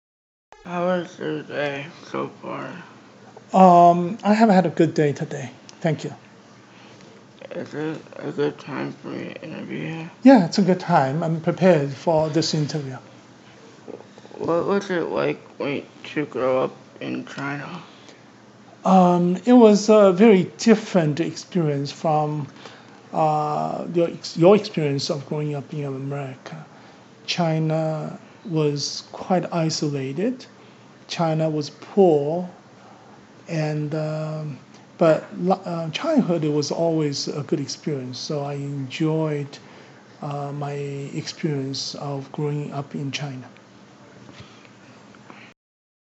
Interview-cut-2.mp3